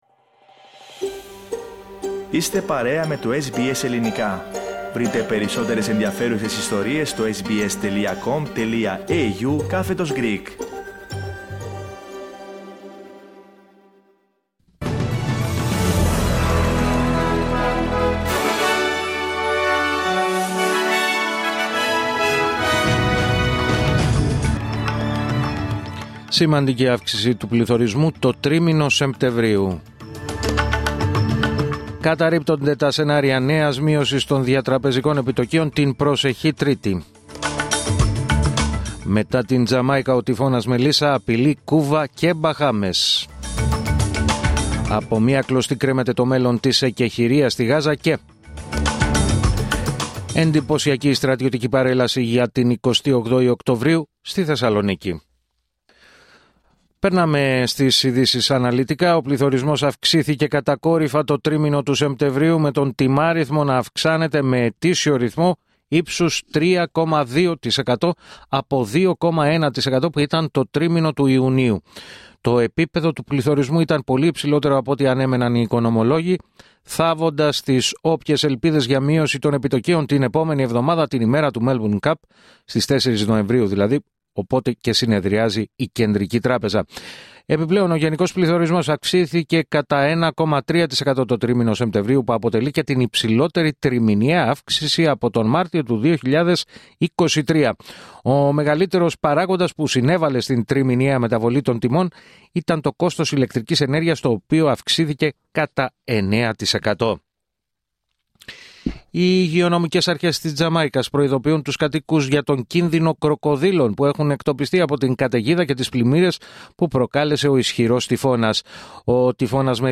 Δελτίο Ειδήσεων Τετάρτη 29 Οκτωβρίου 2025